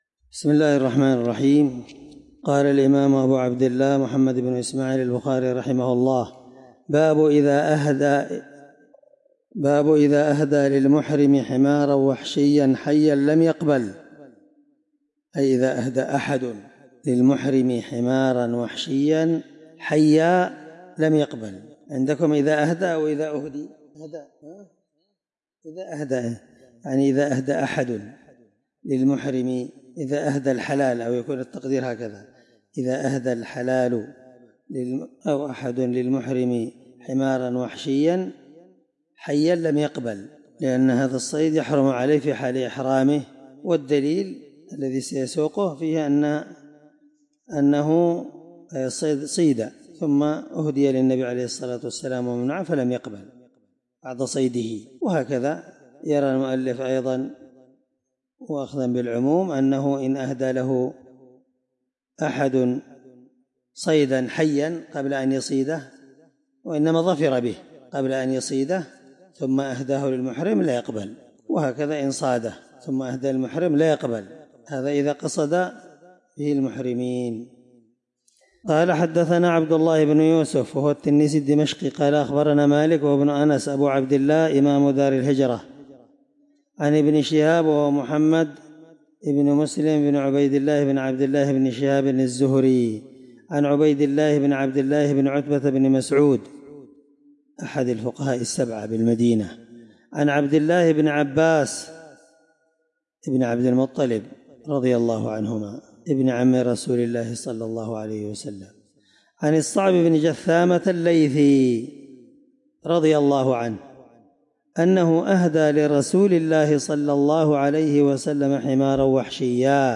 الدرس 5من شرح كتاب جزاء الصيد حديث رقم(1825 )من صحيح البخاري